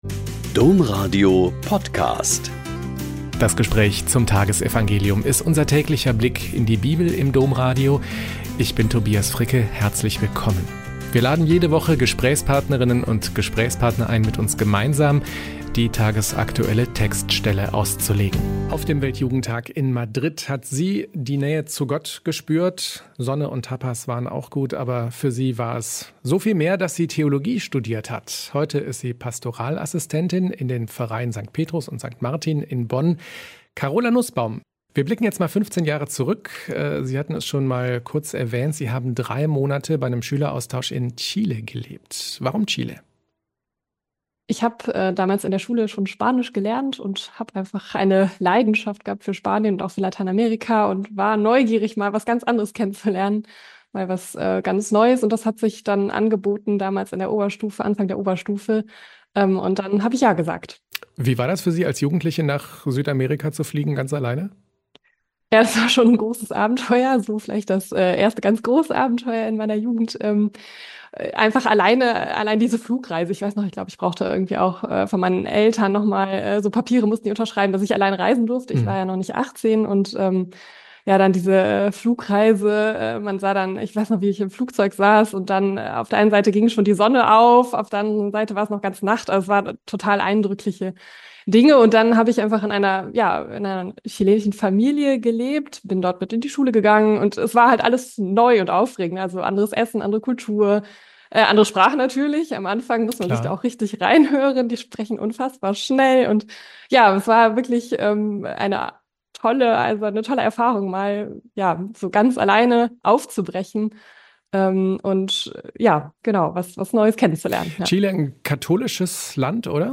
Mt 14,1-12 - Gespräch